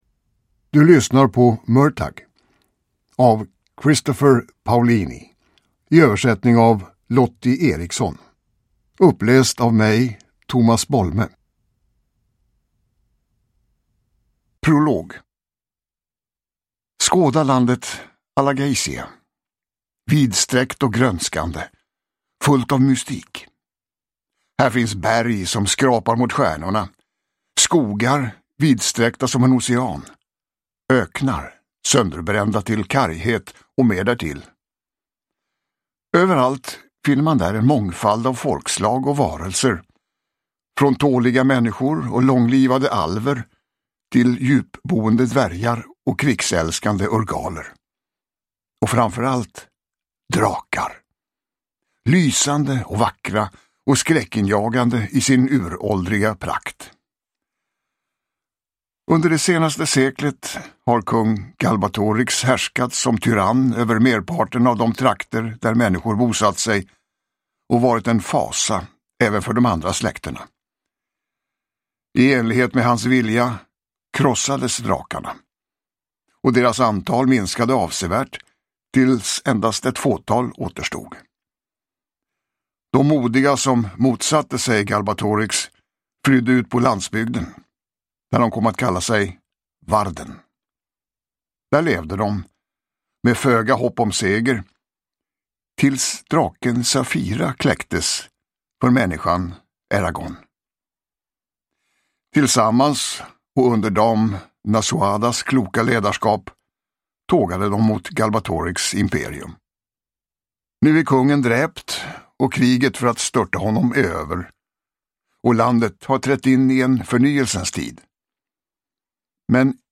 Murtagh – Ljudbok – Laddas ner
Uppläsare: Tomas Bolme